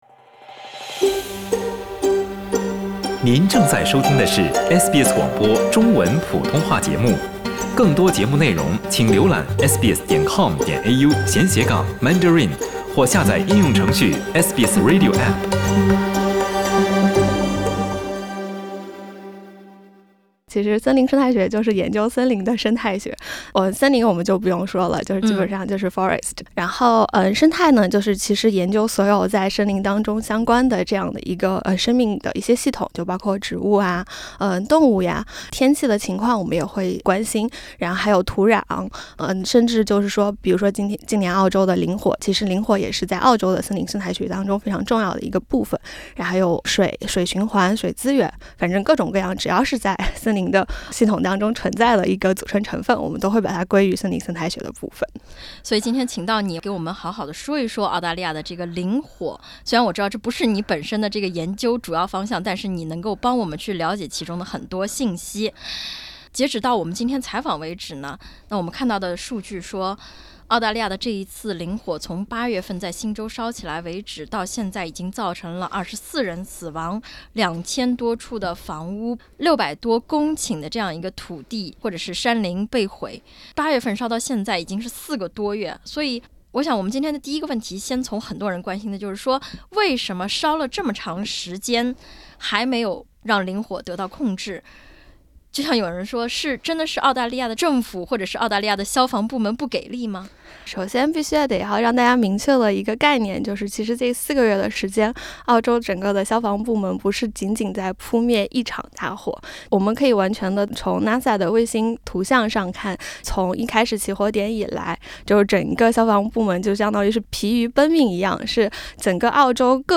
欢迎点击封面图片收听详细采访。